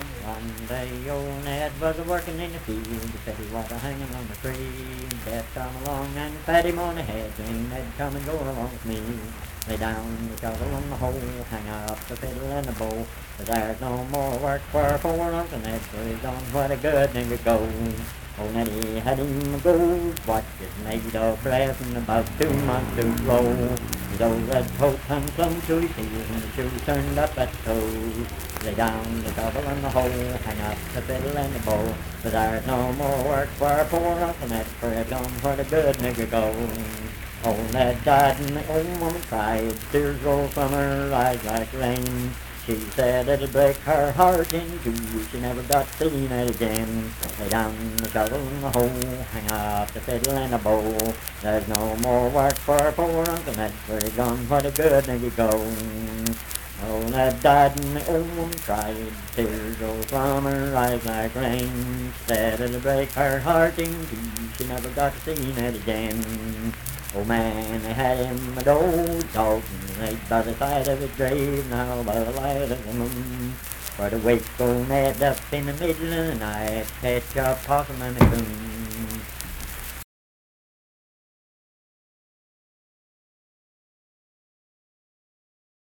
Unaccompanied vocal music
Performed in Dundon, Clay County, WV.
Minstrel, Blackface, and African-American Songs
Voice (sung)